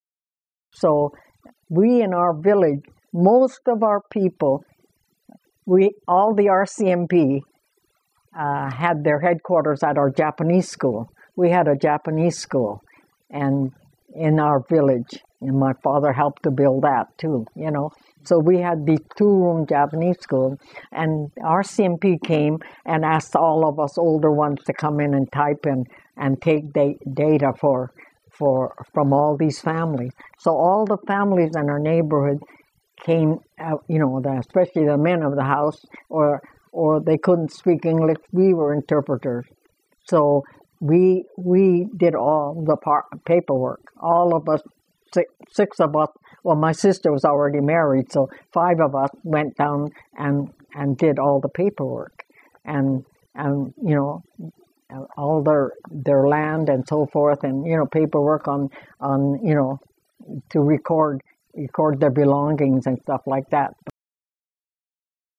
This was one of the longest interviews I've conducted so far.